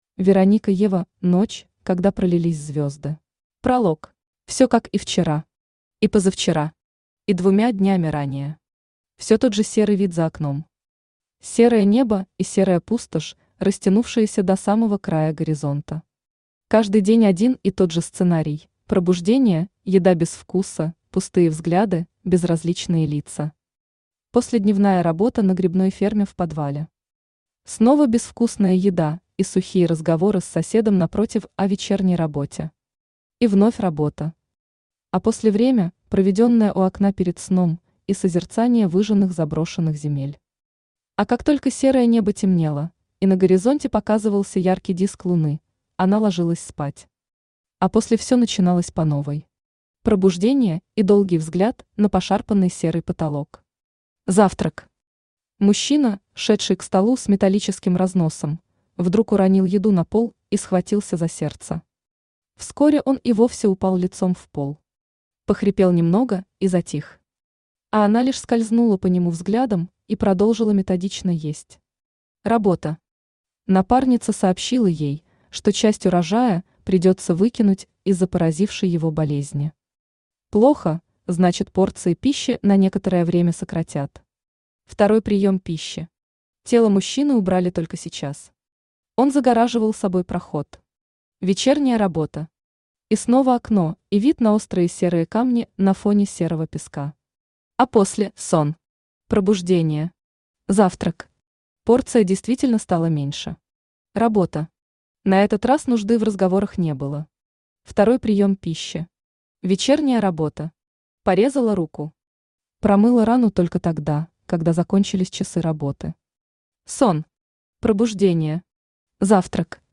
Аудиокнига Ночь, когда пролились звезды | Библиотека аудиокниг
Aудиокнига Ночь, когда пролились звезды Автор Вероника Ева Читает аудиокнигу Авточтец ЛитРес.